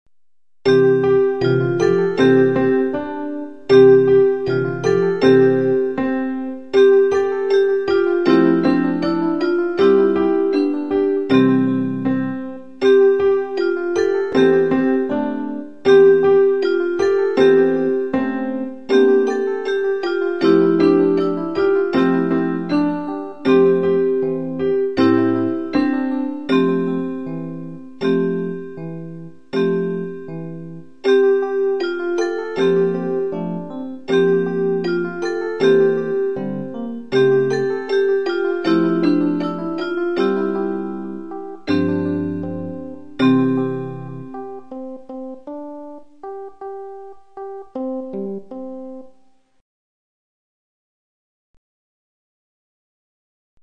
Hely szűke miatt csak 32 Khz-es a minőség, de így is szépen szól.
(A lejátszáshoz a kiírás szerint Sound Blaster Live hangkártyát használtunk. Egyes zenéknél így is előfordulhat, hogy más, illetve másként szólal meg, mint a szerző remélte.)